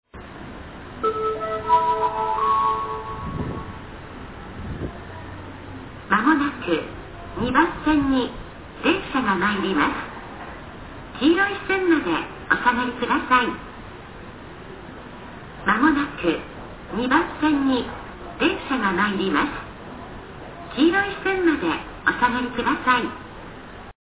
「久里浜２番線接近放送」
Kurihama2sekkinn.mp3